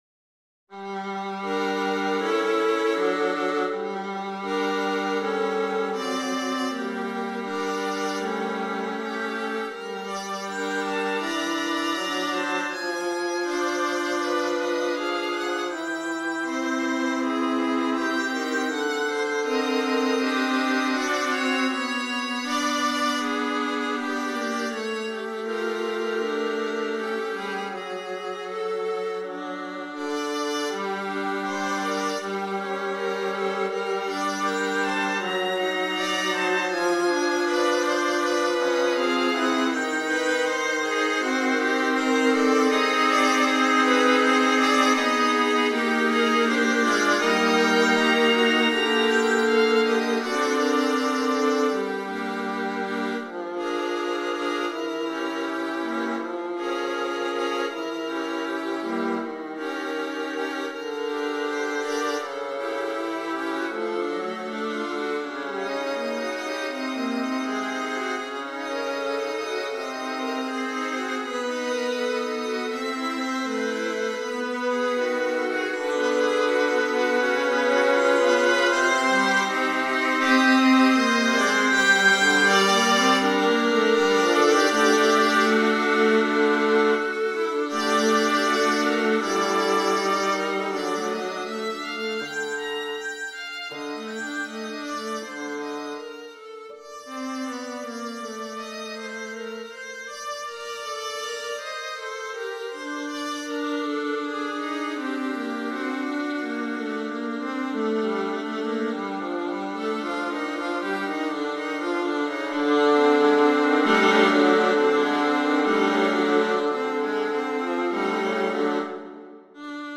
viola quartets